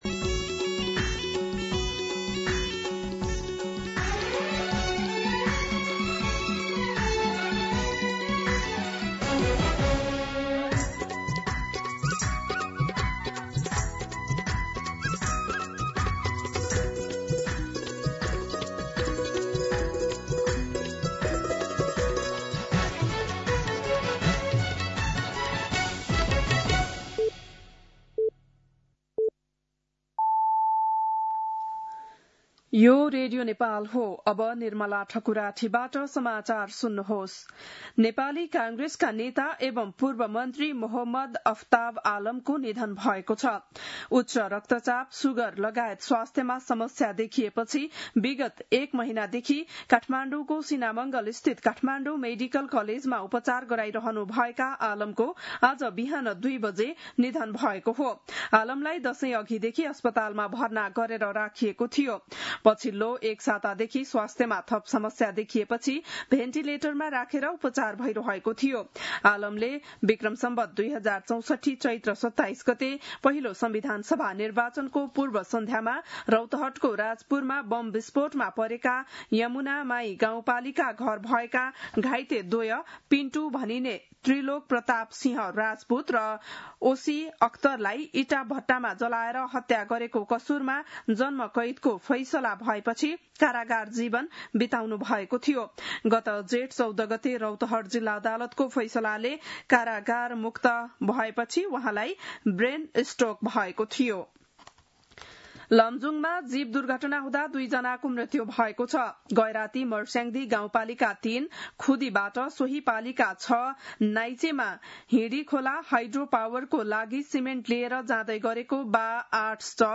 बिहान ११ बजेको नेपाली समाचार : २२ कार्तिक , २०८२
11-am-Nepali-News-2.mp3